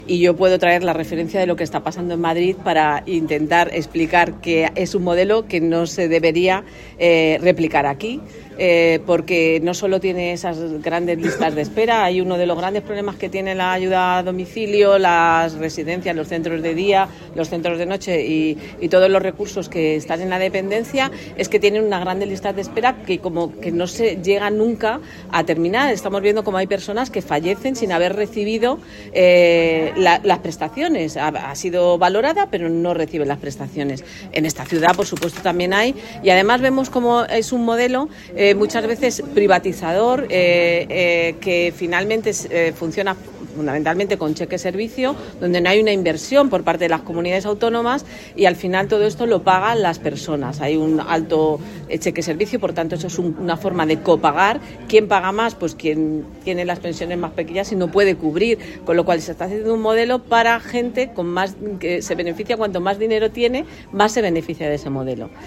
Por su parte, Ana Isabel Lima, concejala del PSOE en el Ayuntamiento de Madrid que ha participado en el Foro, explicó “lo que está pasando en Madrid” con la Dependencia, en lo que es “un modelo que no se debería replicar en Andalucía”.